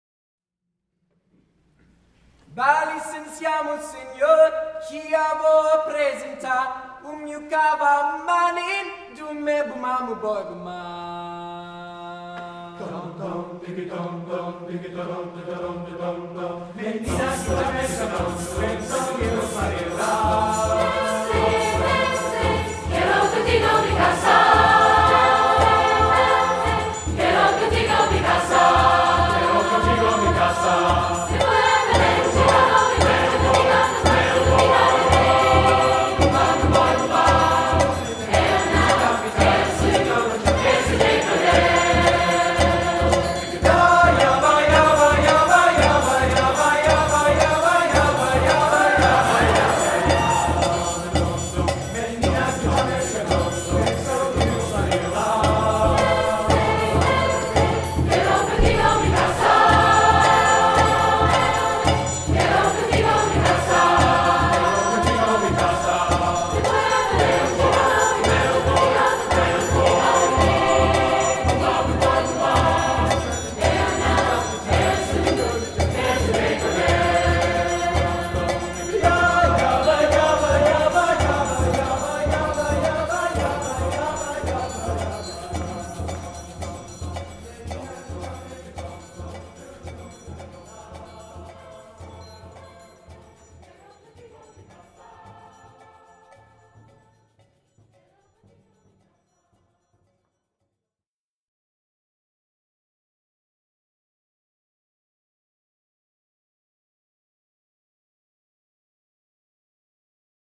Youth Choirs Concert